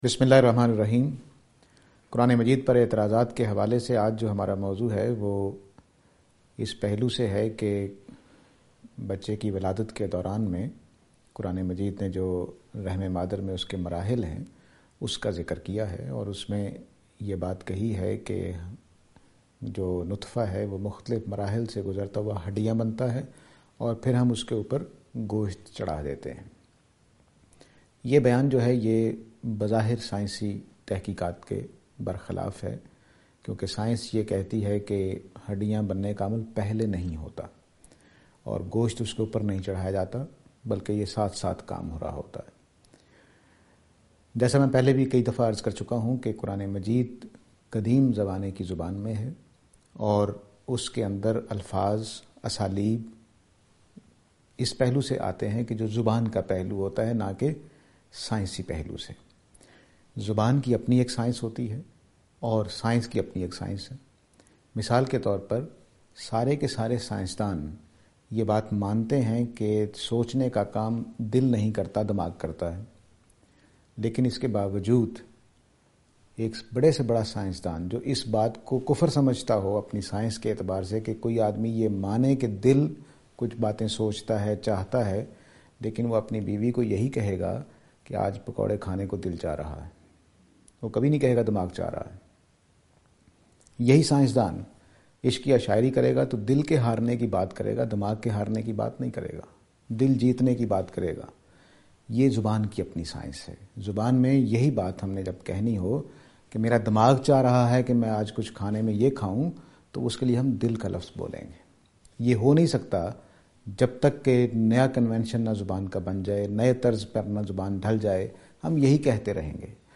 This lecture will present and answer to the allegation "Steps of our birth in mother’s womb".